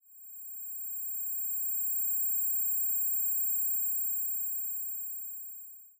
tinnitus3a.ogg